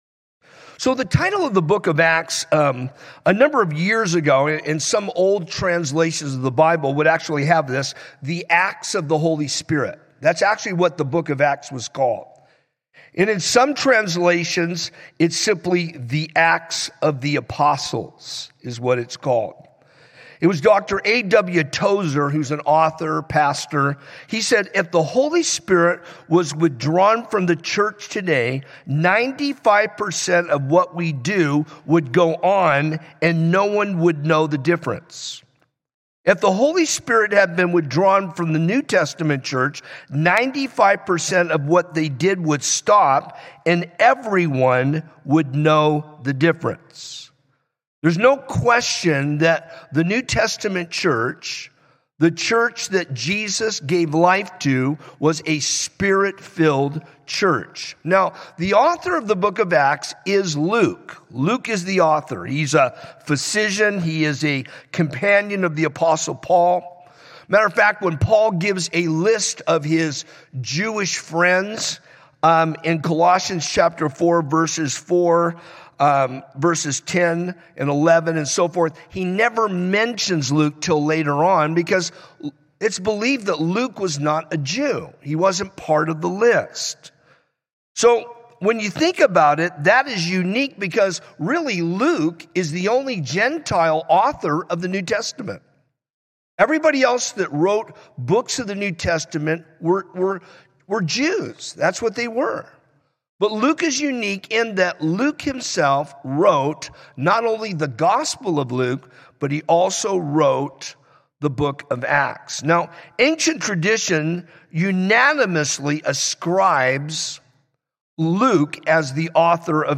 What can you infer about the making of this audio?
Calvary Chapel Rialto